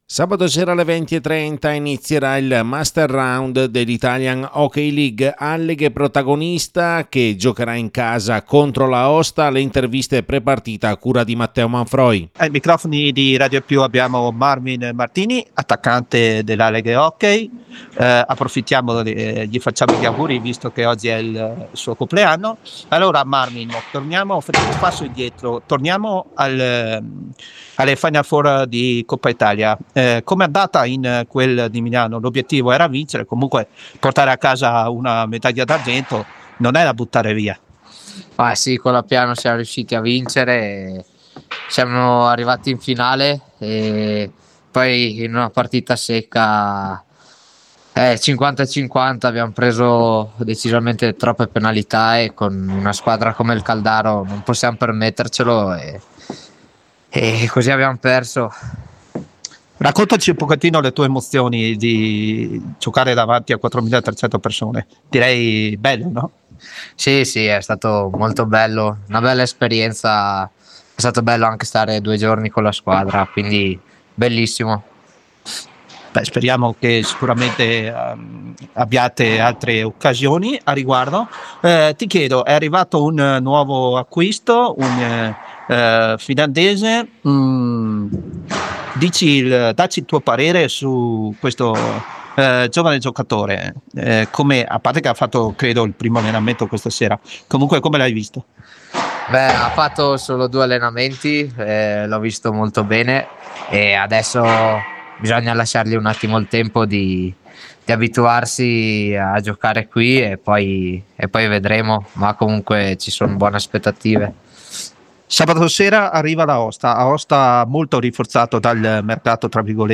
LE INTERVISTE PRE PARTITA IN VISTA DI ALLEGHE – AOSTA, PRIMA GIORNATA DEL MASTER ROUND IHL